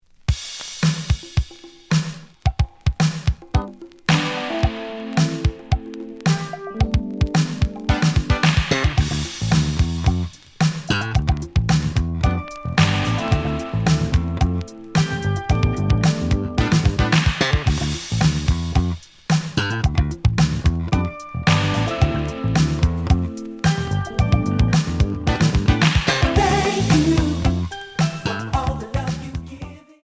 試聴は実際のレコードから録音しています。
●Genre: Soul, 80's / 90's Soul
●Record Grading: VG+ (両面のラベルに若干のダメージ。多少の傷はあるが、おおむね良好。)